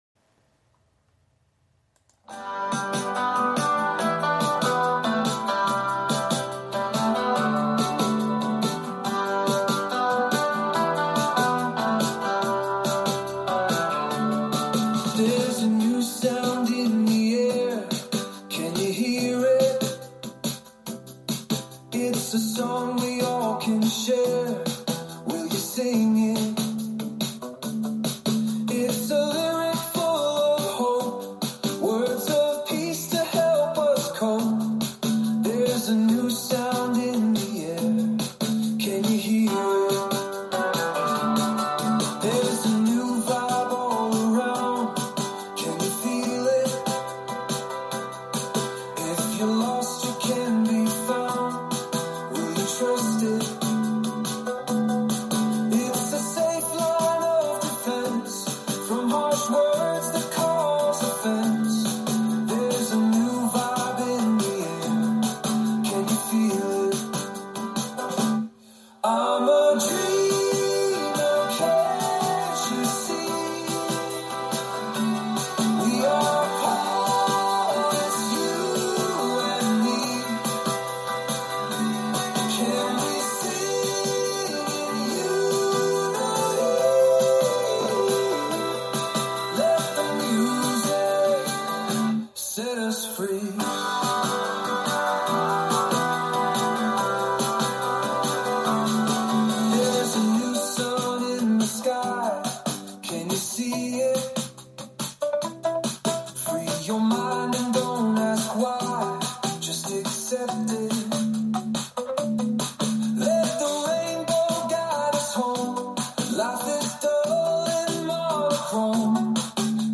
Music is AI generated.
upliftinglyric
I was enchanted by the beautiful lyrics and energetic rhythm of this song.
I thrilled to the beautiful words and upbeat rhythm of this song
Love the message, love the music...50s doowop vibe, and love love love that you can dance to it!